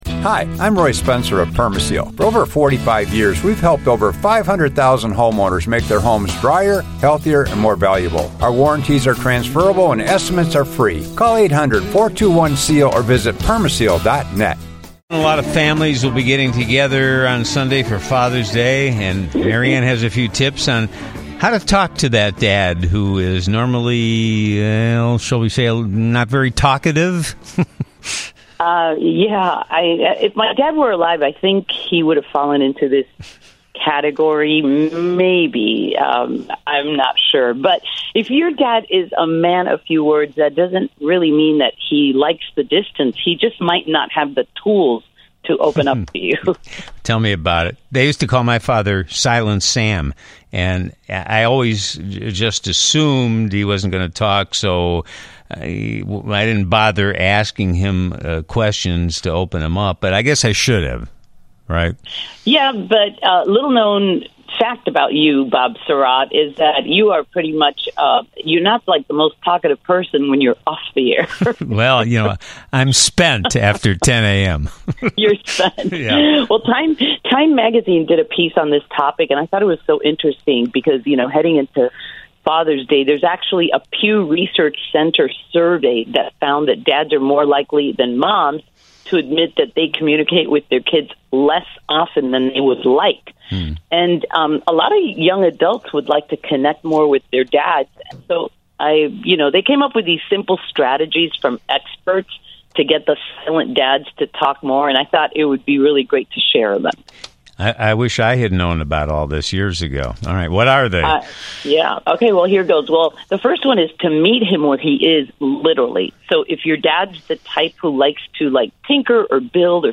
a weekly segment following the 8:30 am newscast